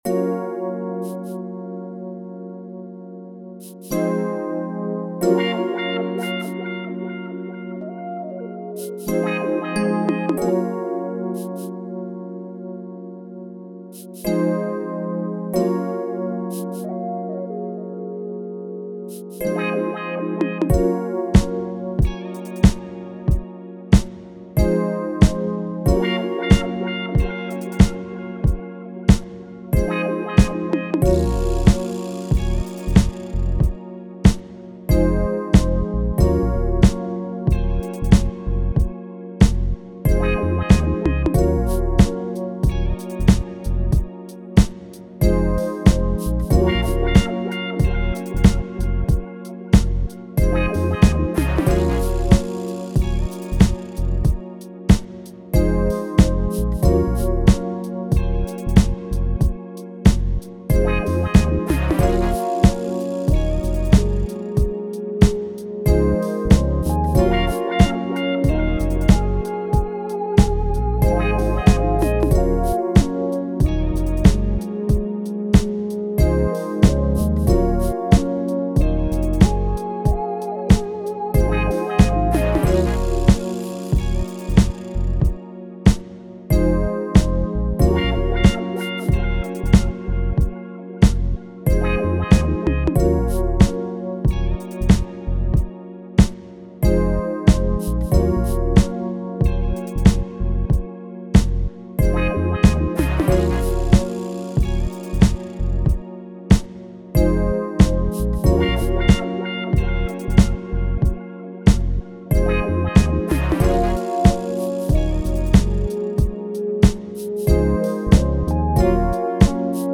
R&B, 80s
Bb Minor